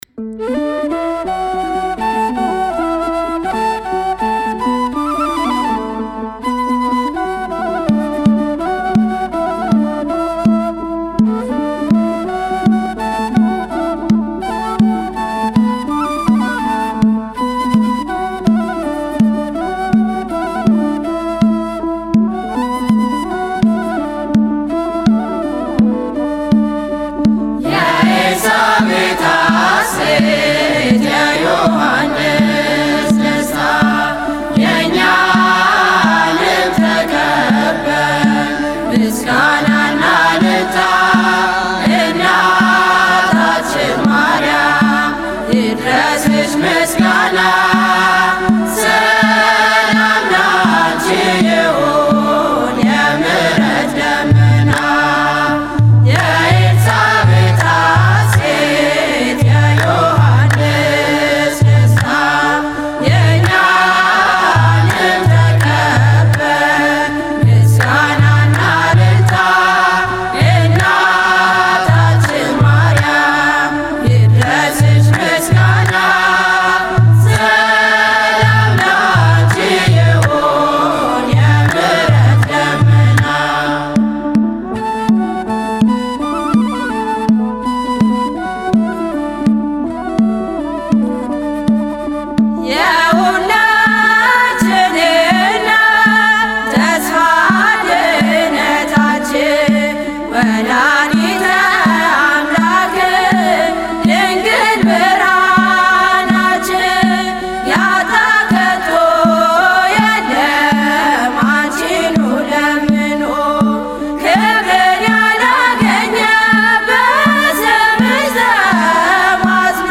መዝሙር (የኤልሳቤጥ ሐሴት) May 13, 2018